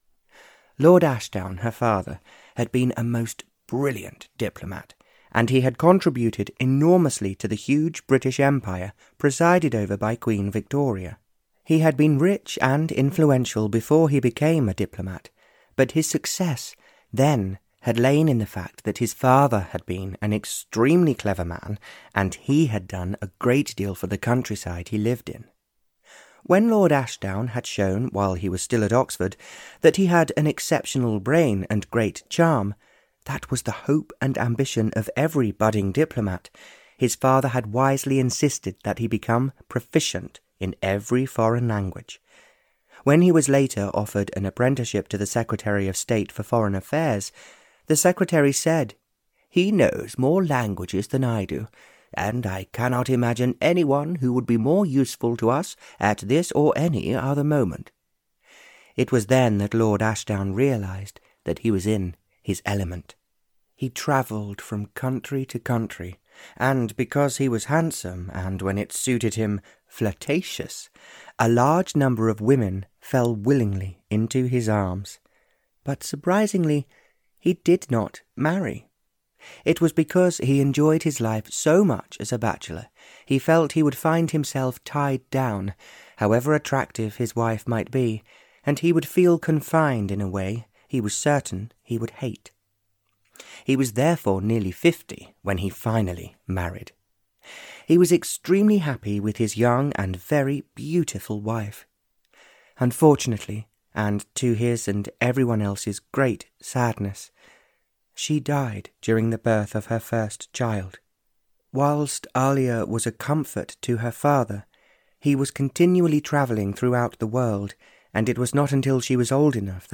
Audio knihaAn Archangel Called Ivan (Barbara Cartland's Pink Collection 108) (EN)
Ukázka z knihy